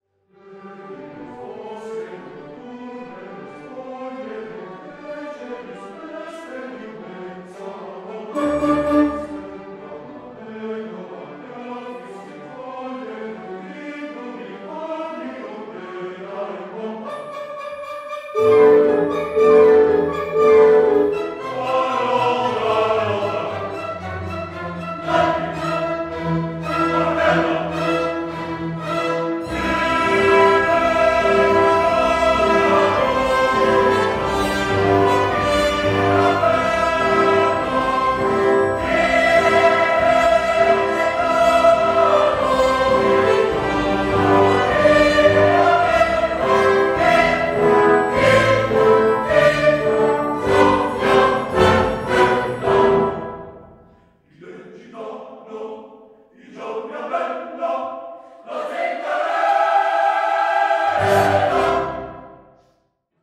Live recording
soprano